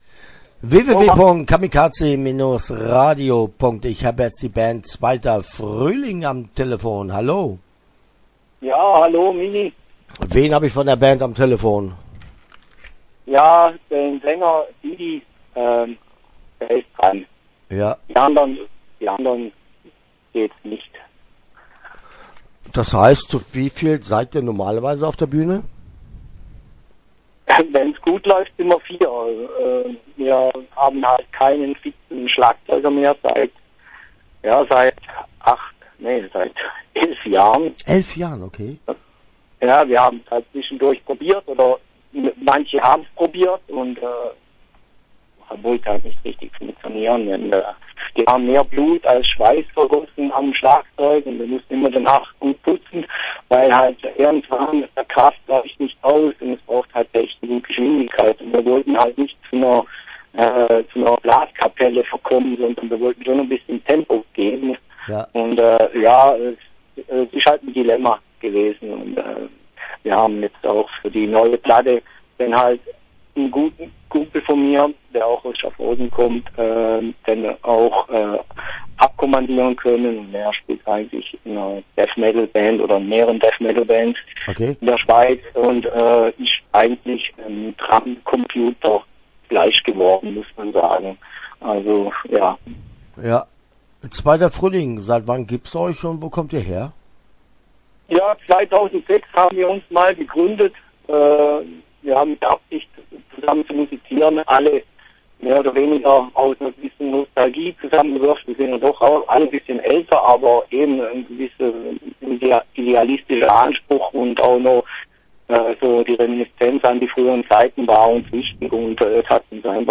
2ter Fruehling - Interview Teil 1 (10:56)